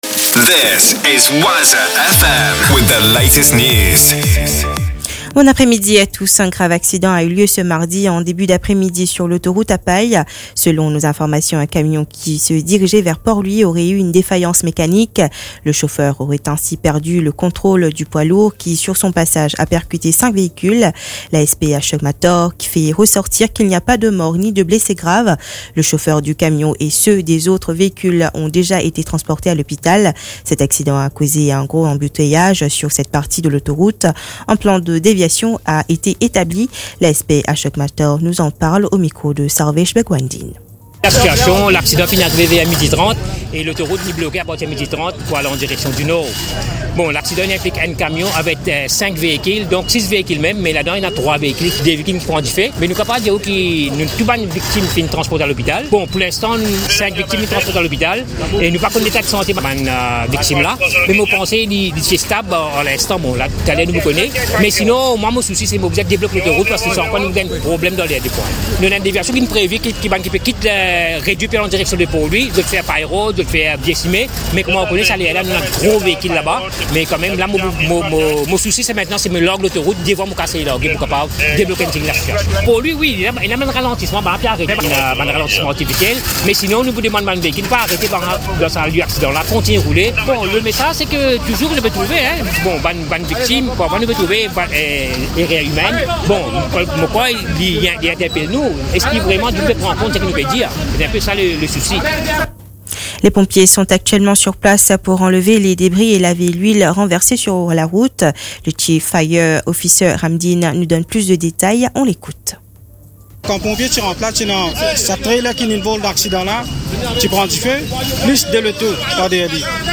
NEWS 15H - 26.12.23